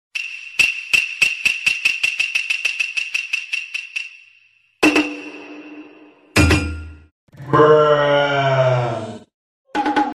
Еще сильнее замедлили